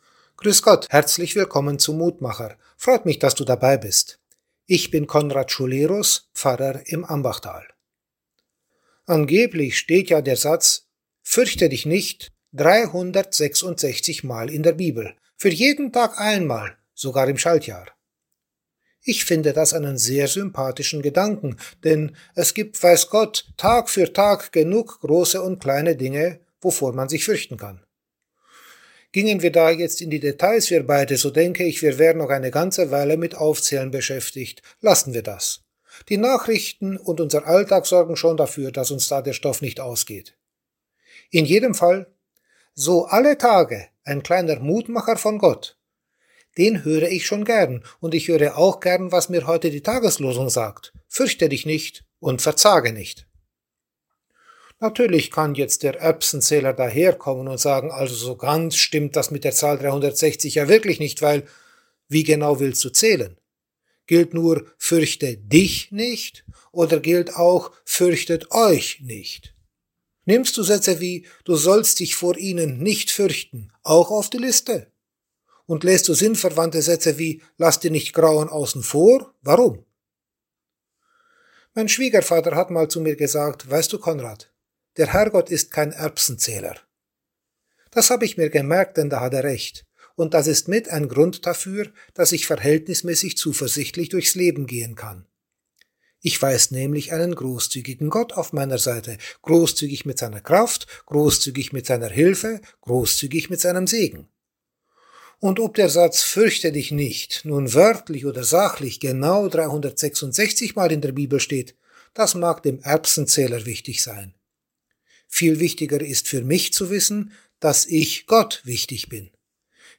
Kurzer täglicher Andachtsimpuls zu Losung oder Lehrtext des Herrnhuter Losungskalender